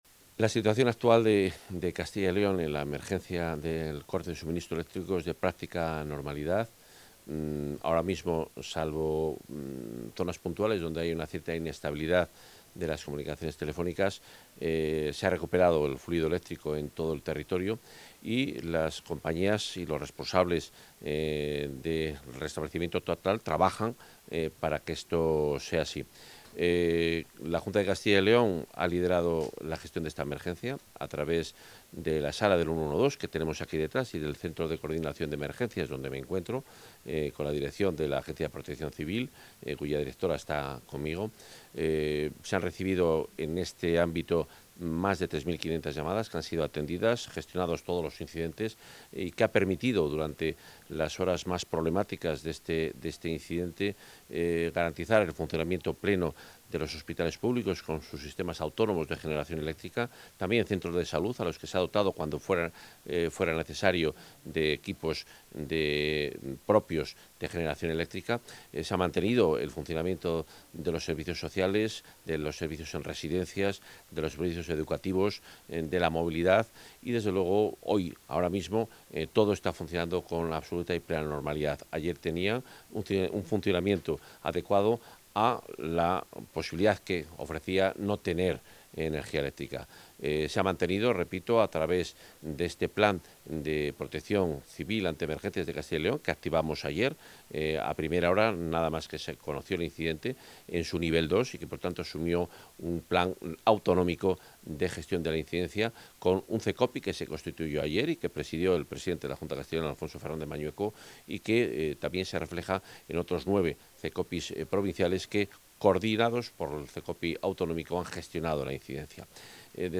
Declaraciones del consejero.